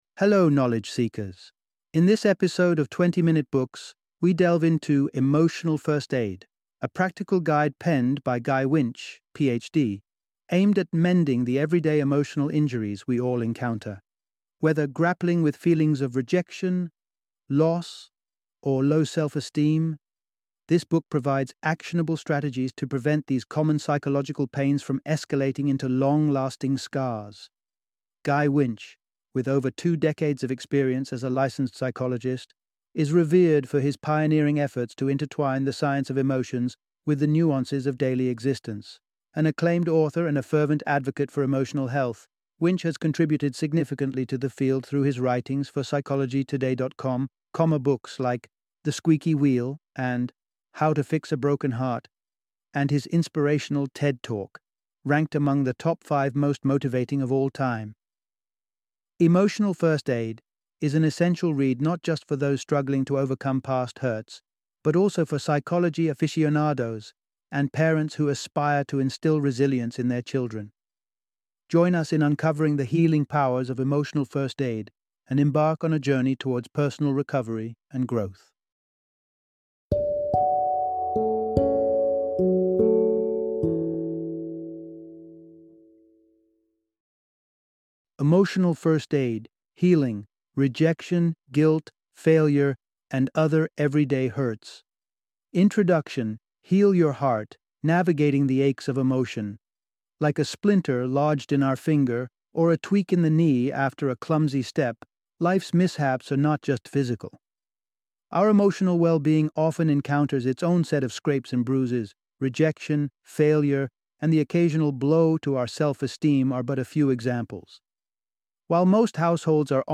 Emotional First Aid - Audiobook Summary